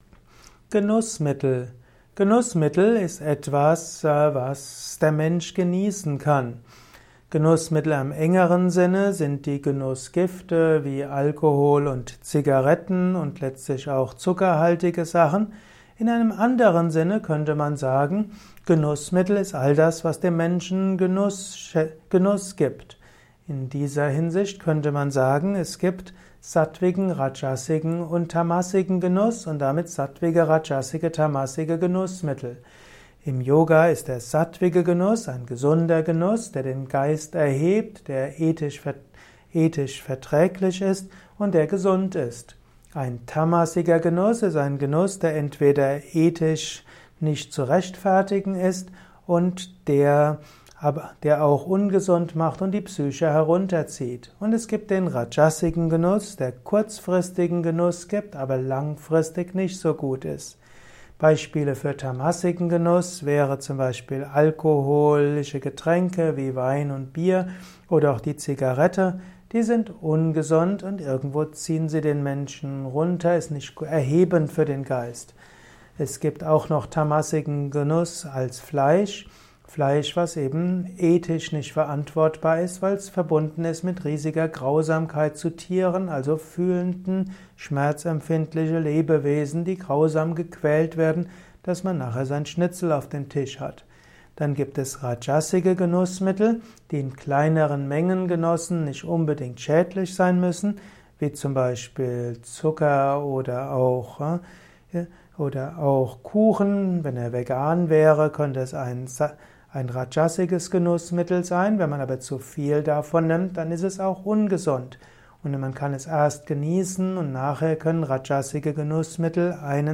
Ein Kurzvortrag über Genussmittel